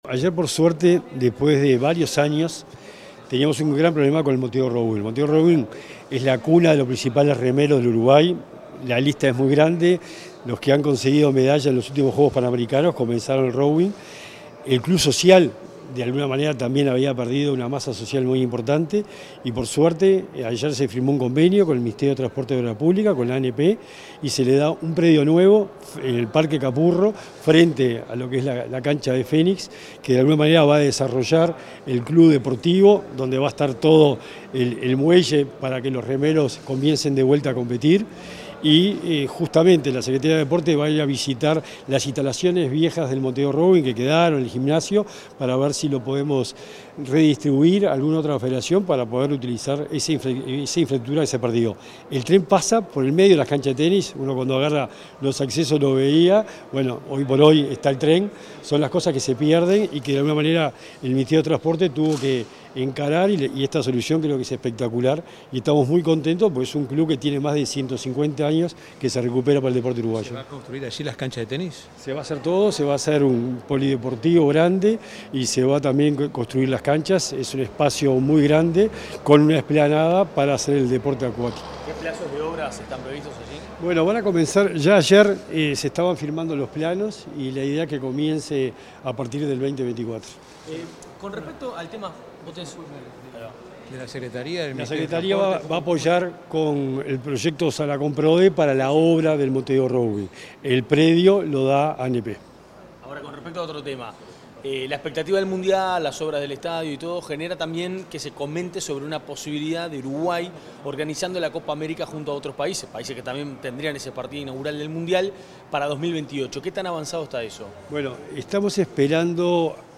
Declaraciones del secretario nacional del Deporte, Sebastián Bauzá